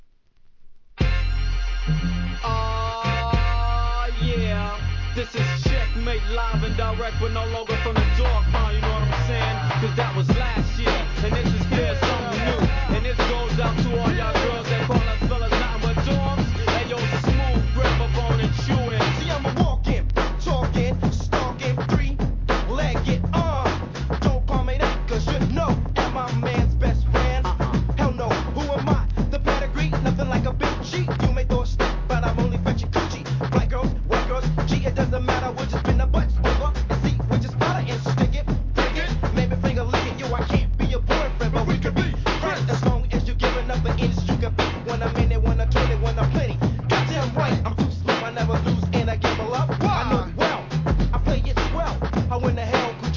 1994年、マイアミ産レア・マイナーHIP HOP!!